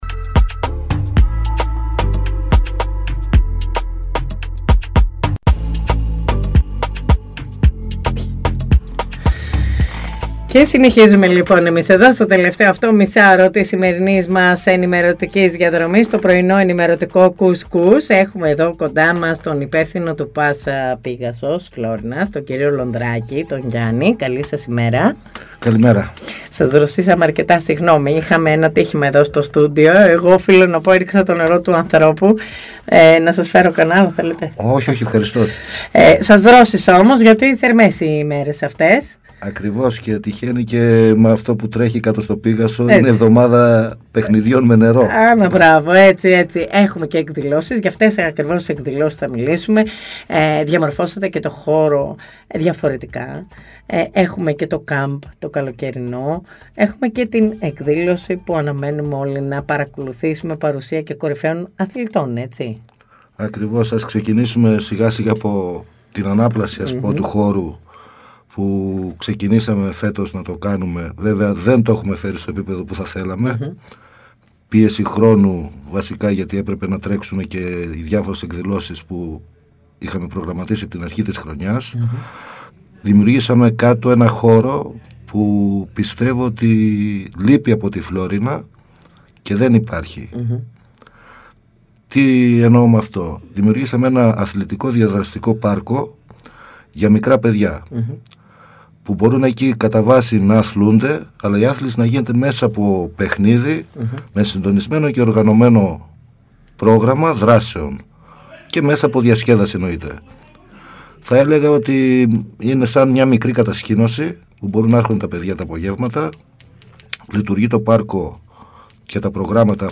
Πρωϊνό ενημερωτικό “κους-κους” με διαφορετική ματιά στην ενημέρωση της περιοχής της Φλώρινας και της Δυτικής Μακεδονίας, πάντα με την επικαιρότητα στο πιάτο σας.